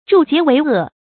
助桀為惡 注音： ㄓㄨˋ ㄐㄧㄝ ˊ ㄨㄟˊ ㄜˋ 讀音讀法： 意思解釋： 同「助桀為虐」。